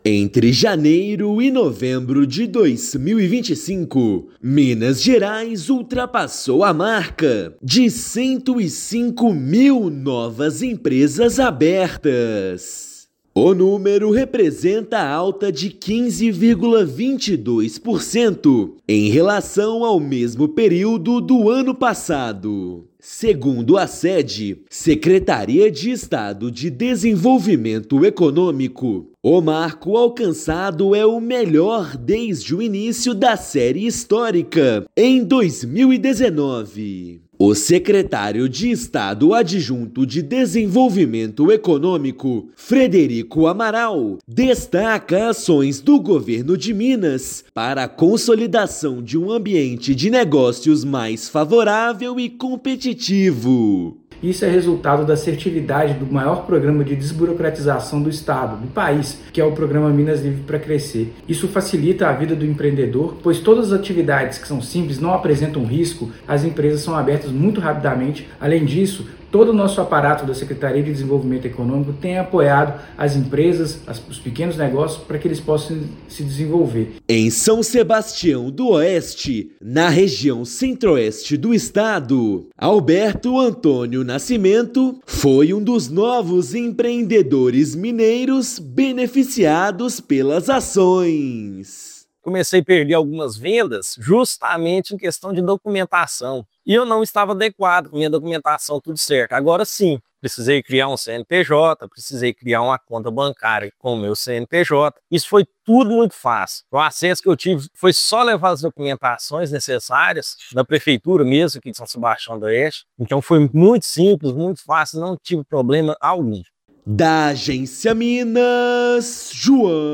Estado alcança o melhor resultado desde o início da série histórica, em 2019, ano em que foram abertos 52.636 novos empreendimentos. Ouça matéria de rádio.